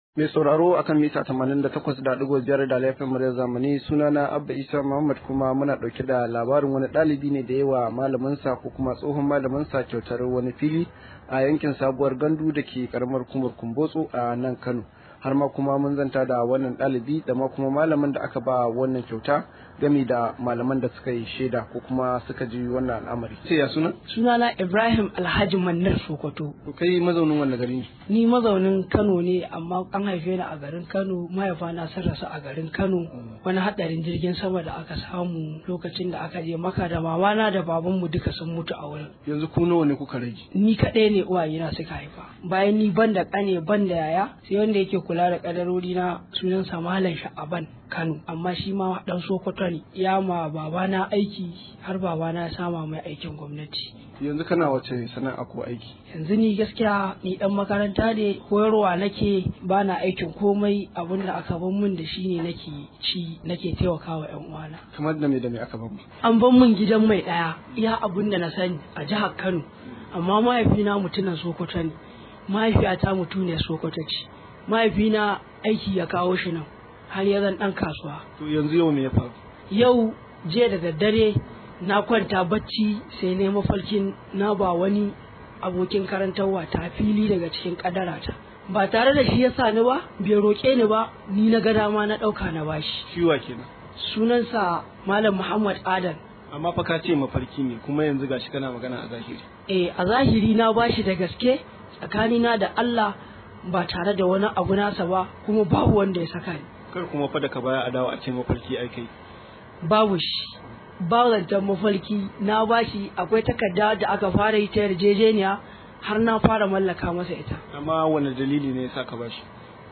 Rahoto: Dalibi ya mallakawa malamin sa fili bisa gudunmawar da yake bayarwa